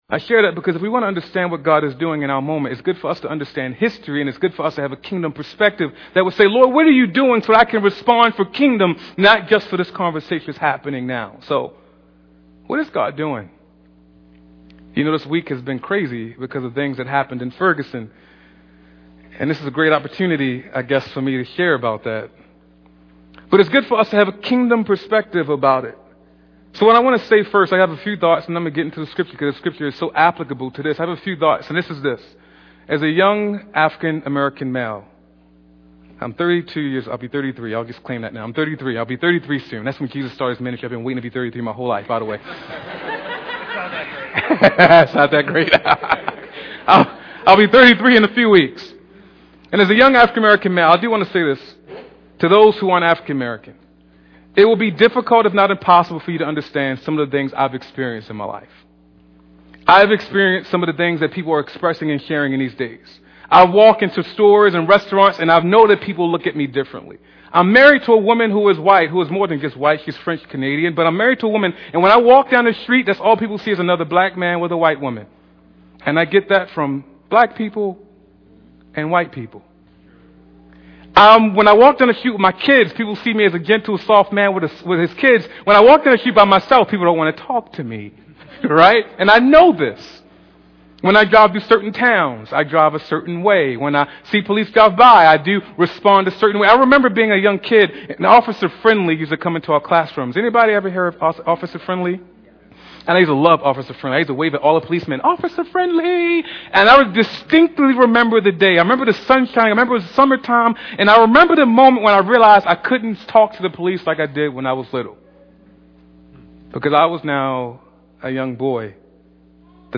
Here are some thoughts I shared with my church this morning. I hope that these thoughts will draw us together rather than push us apart.